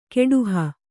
♪ keḍuha